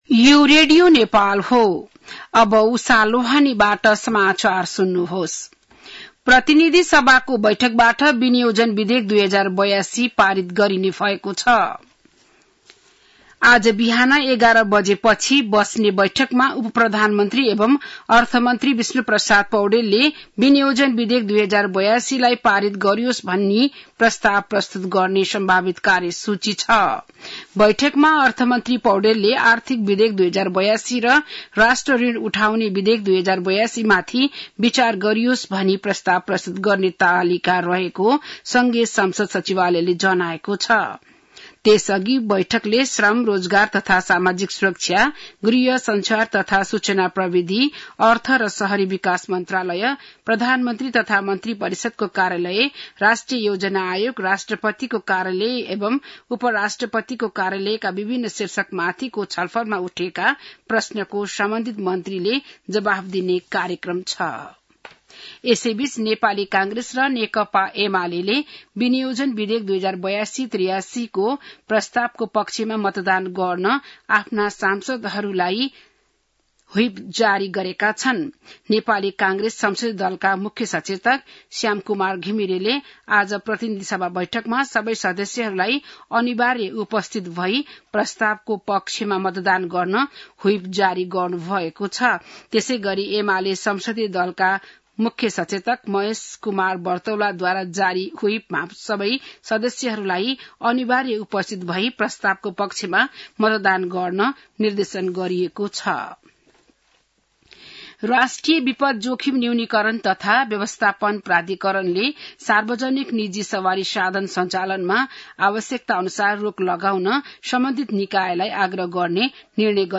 बिहान १० बजेको नेपाली समाचार : १० असार , २०८२